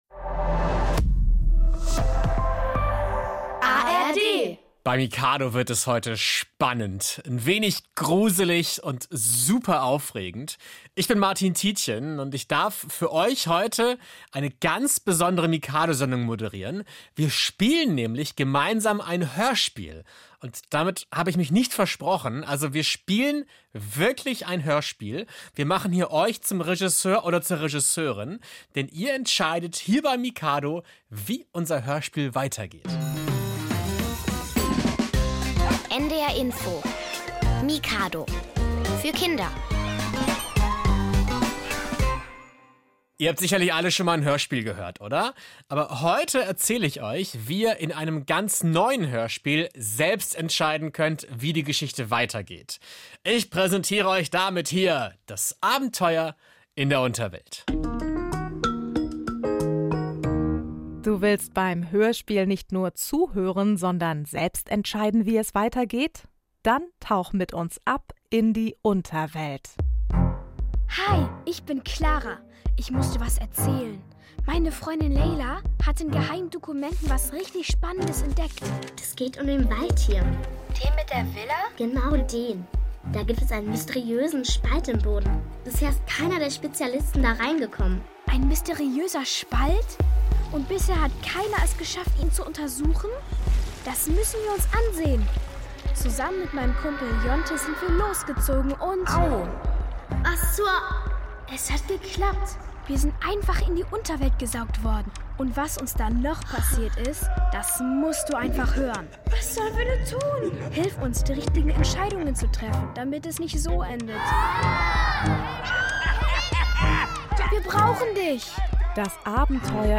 Aktuelle Themen mit Aha-Effekt - zum Lachen, Lernen und Weitersagen. Bei uns kommen Kinder und Experten zu Wort, es gibt Rätsel, Witze, Reportagen, Buch- und Basteltipps, Experimente und Musik.